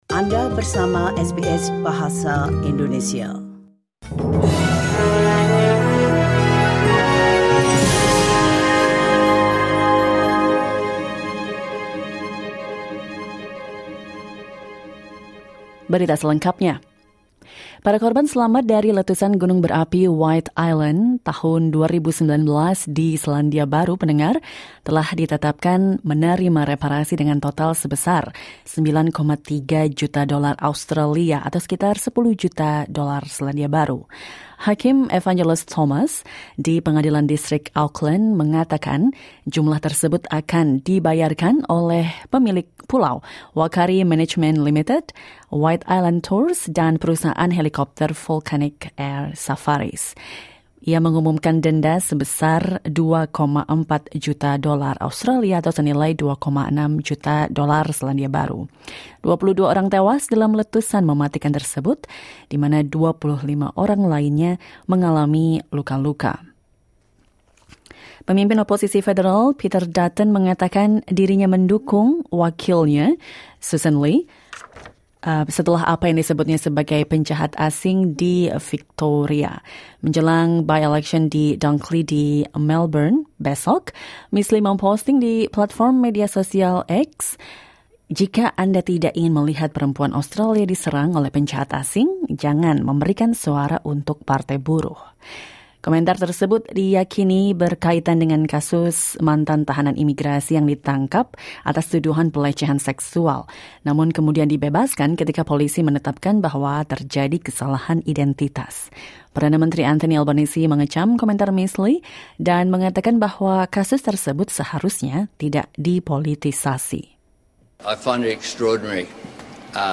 SBS Audio news in Indonesian, 1 March 2024.